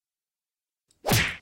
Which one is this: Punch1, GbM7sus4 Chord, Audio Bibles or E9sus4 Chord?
Punch1